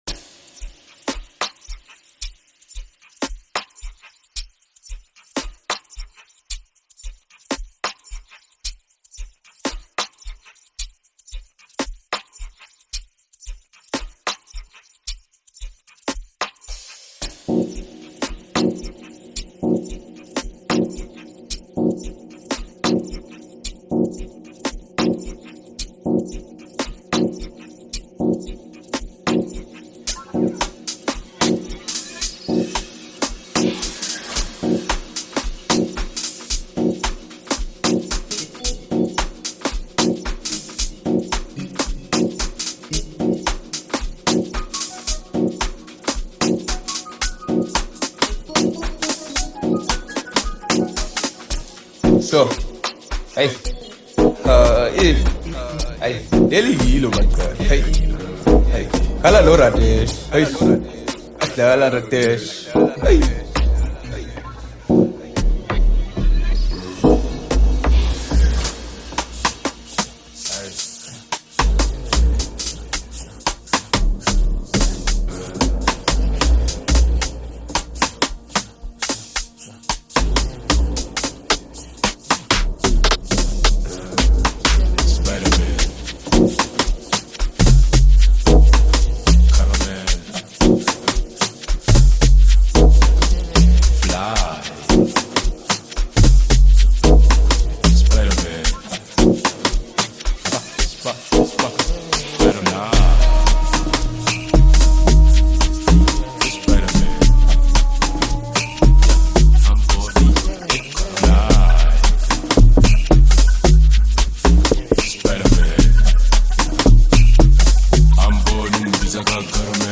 filled with contagious beats and heartfelt melodies.